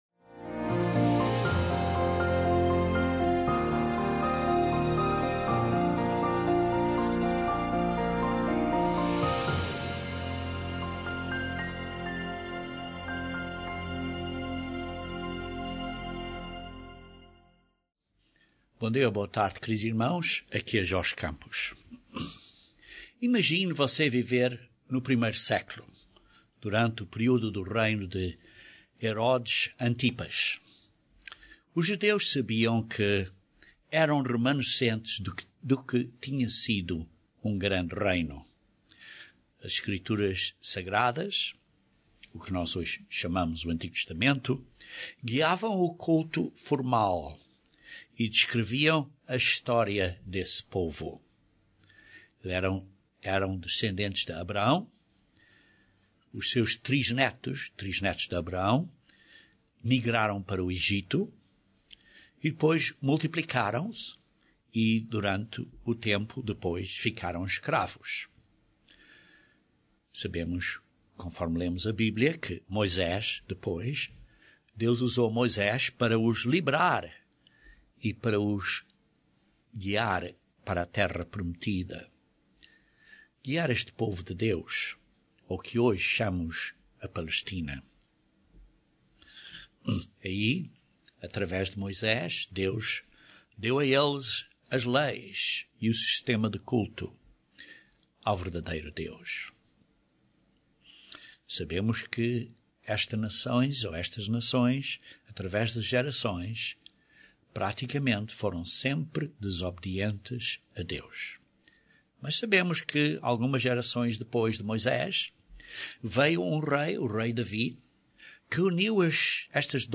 Este sermão descreve um cenário do que eles podiam ter falado e analisado. As escrituras do Antigo Testamento que apontam para o sacrifício de Cristo são explicadas ao longo das escrituras que apontam para a Sua segunda vinda para estabelecer o Reino de Deus na Terra.